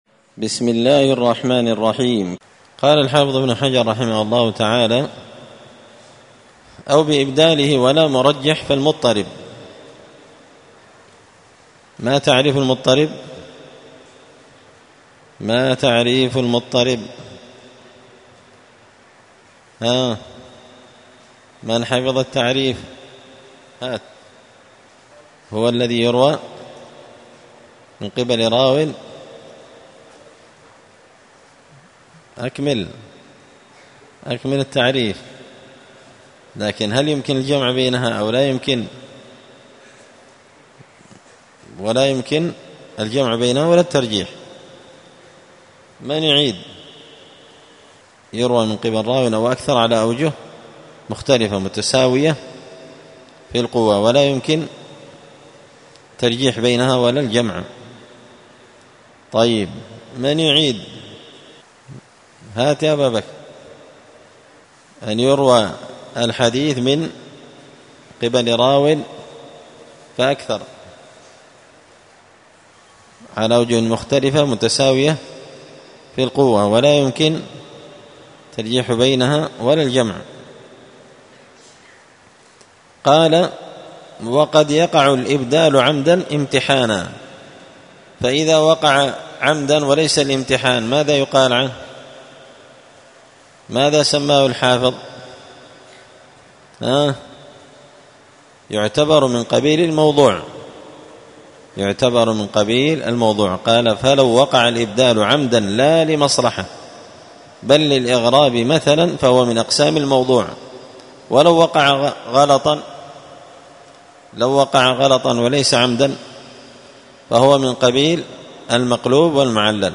تعليق وتدريس الشيخ الفاضل: